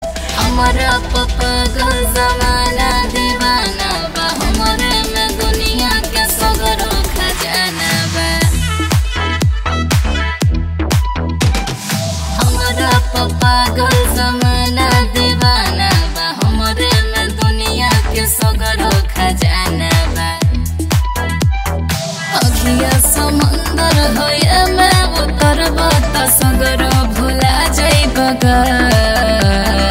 Bhojpuri Songs